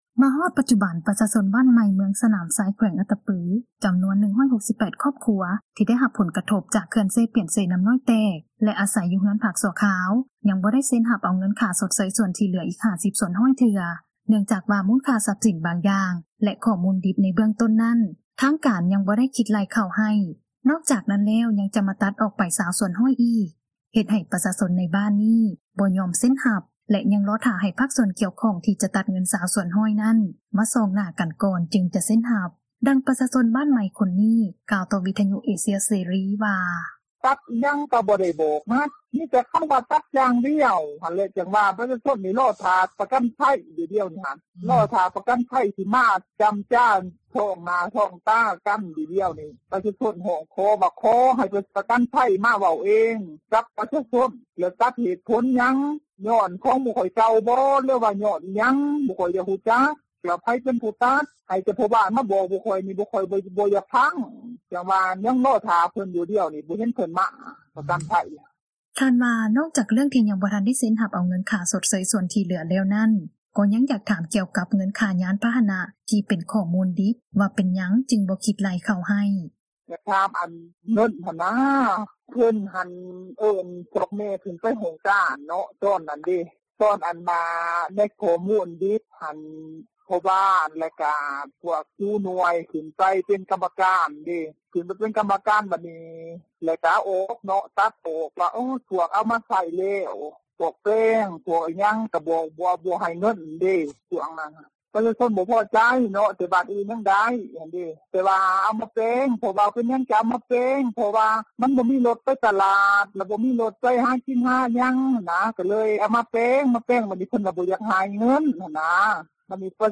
ເຮັດໃຫ້ປະຊາຊົນ ໃນບ້ານນີ້ ບໍ່ຍອມເຊັນຮັບ ແລະ ຍັງຖ້າໃຫ້ພາກສ່ວນກ່ຽວຂ້ອງ ທີ່ຈະຕັດເງິນ 20% ນັ້ນ ມາຊ້ອງໜ້າ ກັນກ່ອນ ຈຶ່ງຈະເຊັນຮັບ, ດັ່ງປະຊາຊົນ ບ້ານໃໝ່ ຄົນນີ້ ກ່າວຕໍ່ວິທຍຸເອເຊັຽເສຣີ ວ່າ: